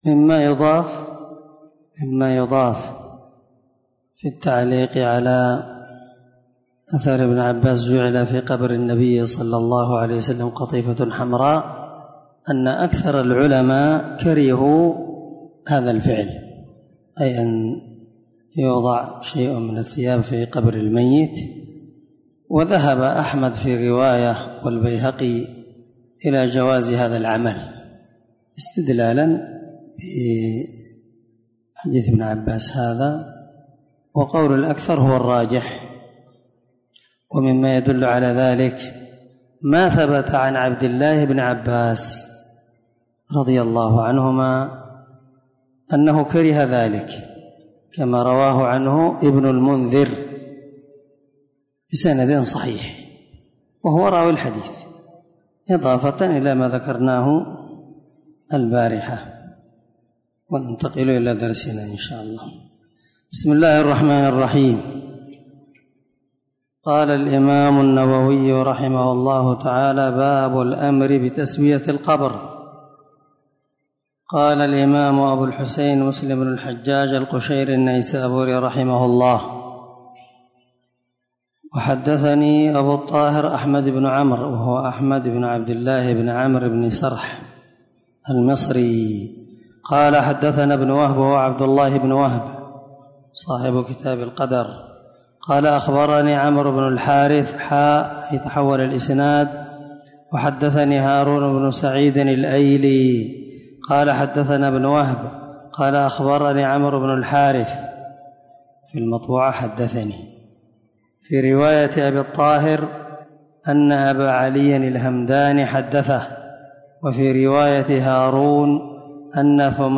585الدرس 30من شرح كتاب الجنائز حديث رقم(968_969) من صحيح مسلم
دار الحديث- المَحاوِلة- الصبيحة.